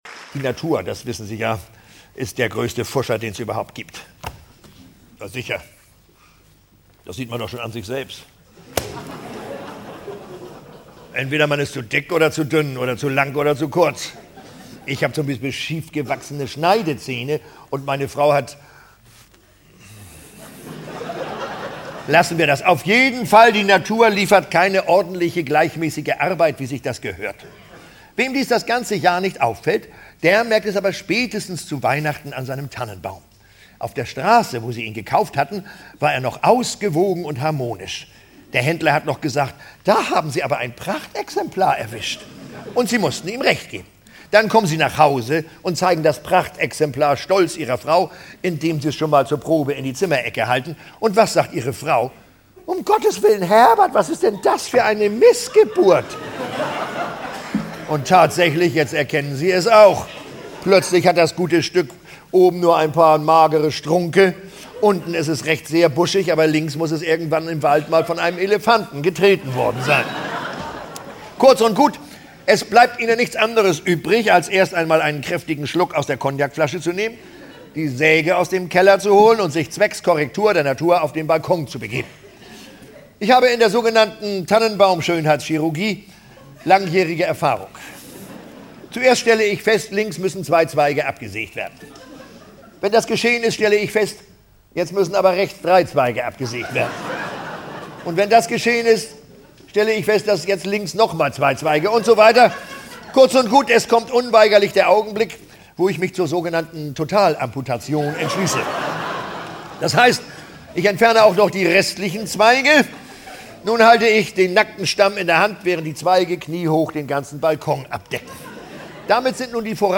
Wer nimmt Oma diesmal? - Hans Scheibner - Hörbuch